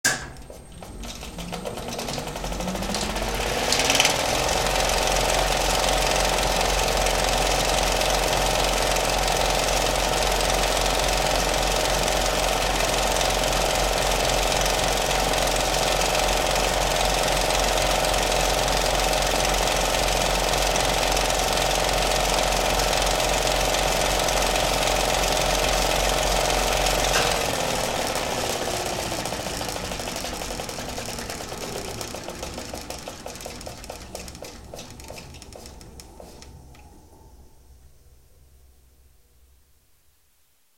Projecteur Cinéma (35mm, Kinoton, FP30) sans ventilateur ni lampe : Mise en route, roullage puis extinction (Prise de son : Devant mécanisme).
Écouter : Projecteur cinéma 35mm #7 (41 s)